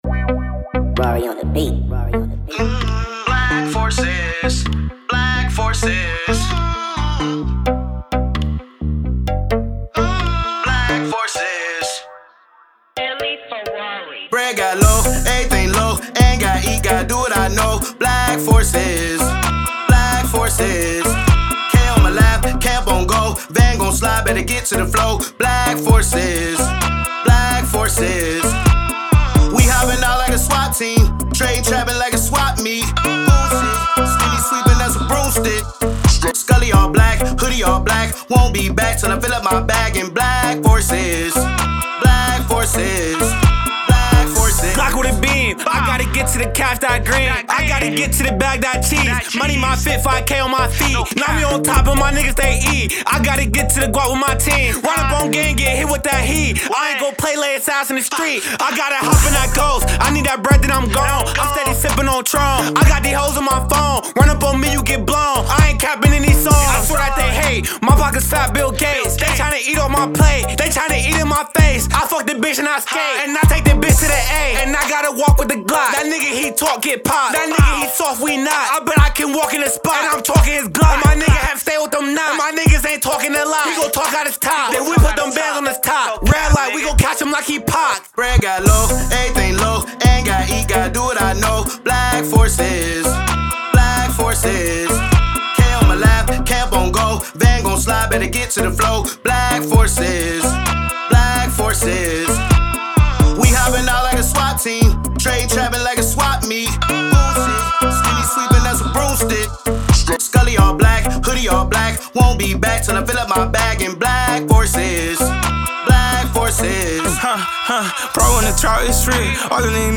Hiphop
creates a hypnotic ode to the black Air Force Ones